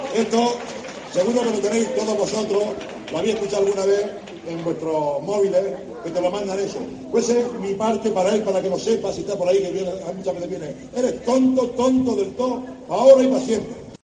En 2019, Arturo Grima, dedicó unas palabras descalificantes al concejal de IU, David Ruiz, que este martes previsiblemente le va a hacer alcalde. El popular parafraseó una de las míticas frases del humorista José Mota ante vecinos simpatizantes y militantes del PP. "Eres tonto del tó para ahora y para siempre", le dijo Ruiz en un un mitin hace cuatro años, la persona que puede convertirle en regidor de Turre.